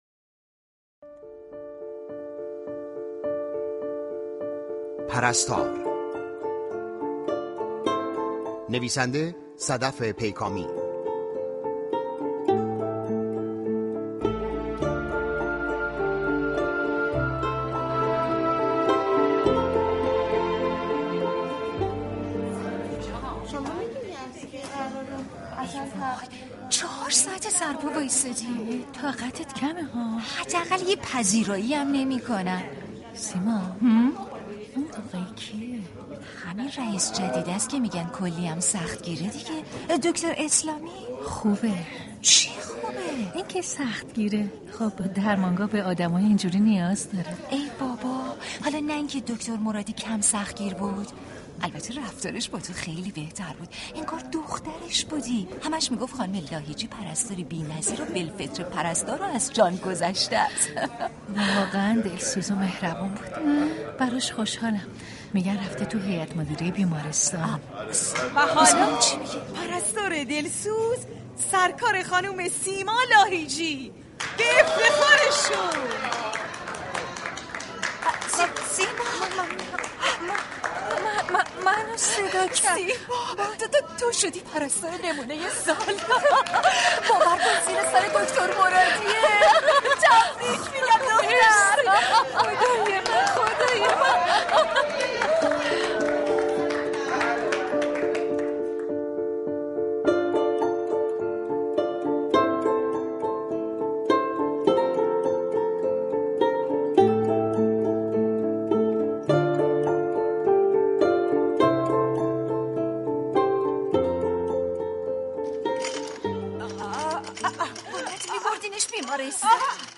همزمان با فرا رسیدن ولادت با سعادت حضرت زینب (س) و فرا رسیدن روز پرستار ، نمایشی با عنوان «پرستار» روی آنتن رادیو نمایش می رود.